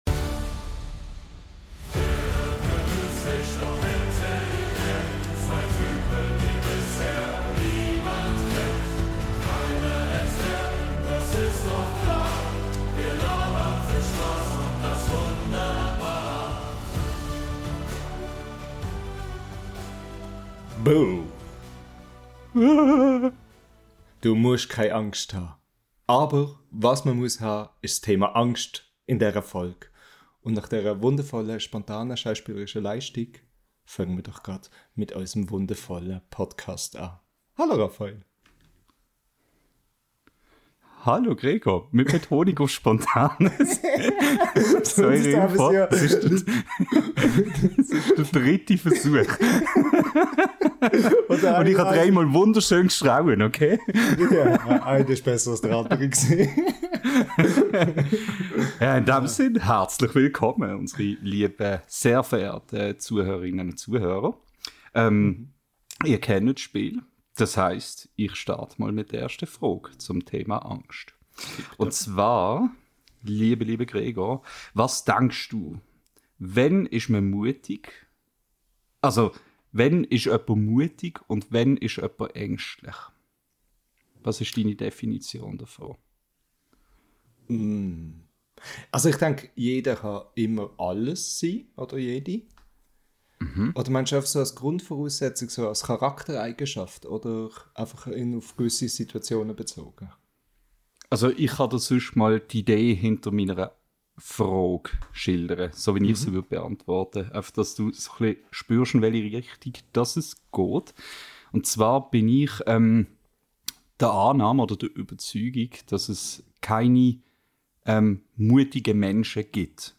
Zitternd, bibbernd, aber mutig stürzen wir uns in dieser neuen Folge unseres schweizerdeutschen Podcasts ins grosse Thema Angst. Wir sprechen über das, was uns schlottern lässt – von alltäglichen Sorgen bis zu Enten mit Agentenpotenzial.